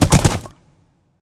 Minecraft Version Minecraft Version snapshot Latest Release | Latest Snapshot snapshot / assets / minecraft / sounds / mob / horse / skeleton / water / gallop4.ogg Compare With Compare With Latest Release | Latest Snapshot
gallop4.ogg